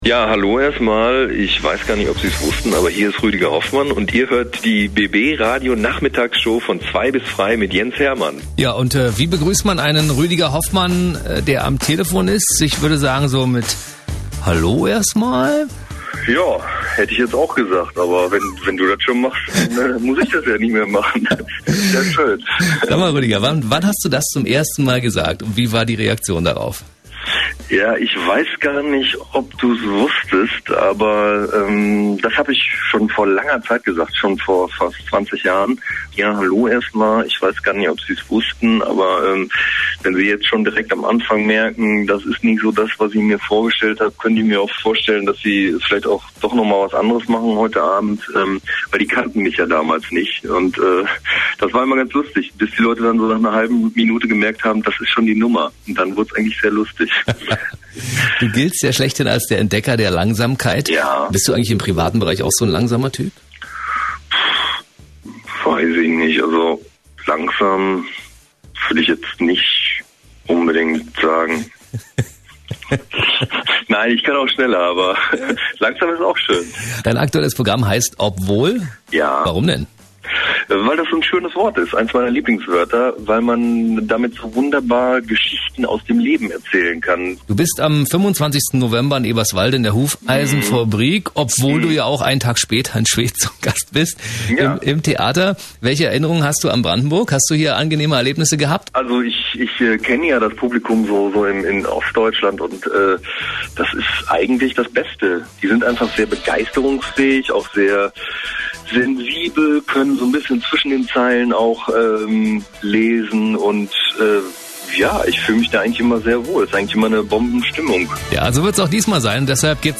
Interview mit Rüdiger Hoffmann
Ich weiß gar nicht, ob Sie’s schon wussten, aber ich hatte Rüdiger Hoffmann an der Strippe. Anlass waren seine beiden Brandenburger Auftritte am 25.11.2010 in Eberswalde und am 26.11.2010 in Schwedt.